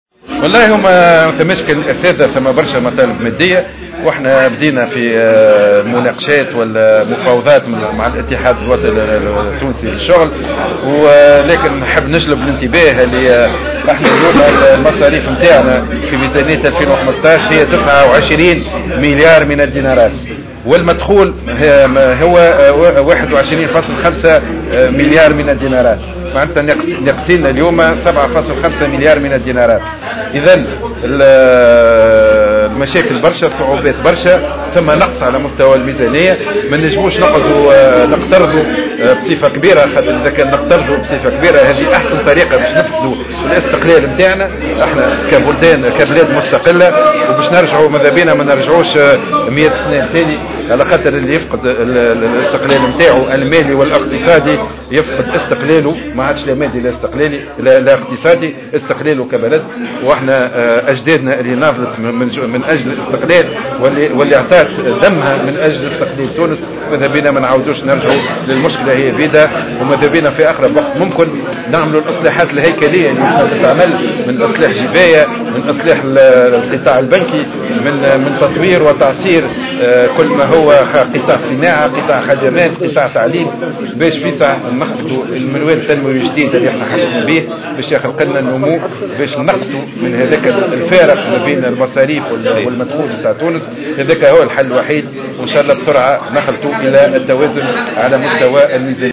أكد وزير المالية سليم شاكر في تصريح